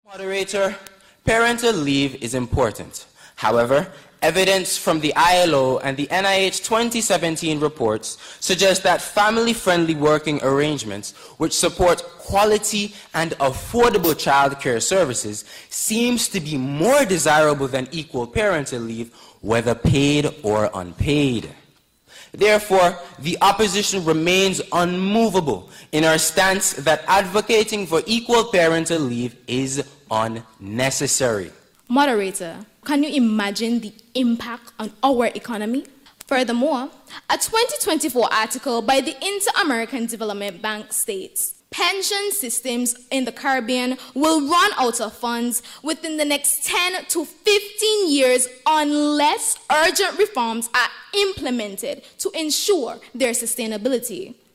The debate took place on Friday, March 7th, where they opposed Team Antigua on the topic: “Parental Leave Should Be Equal for both Mothers and Fathers”.